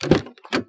门开001
描述：福特蒙迪欧ST24的车门正在打开
标签： 开放 汽车门 福特 手动
声道立体声